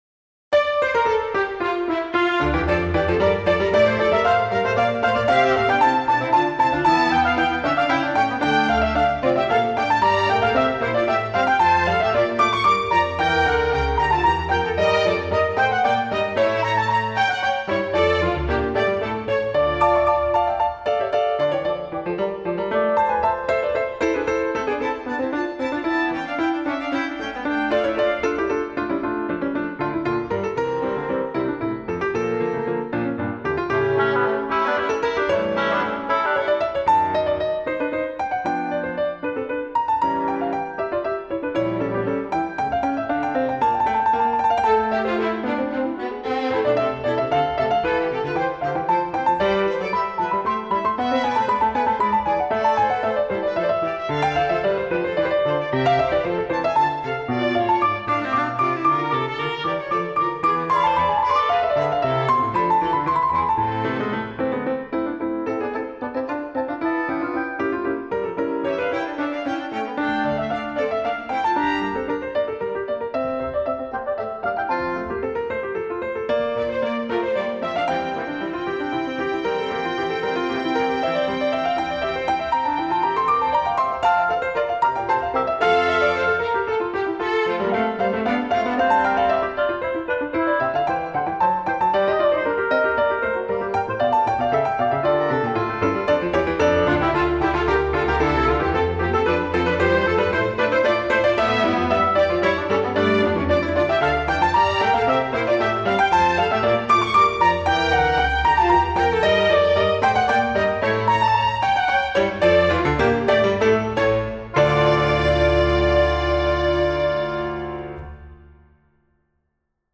Maybe the orchestra is not as close to the conductor as you wish but at least the piano - have fun!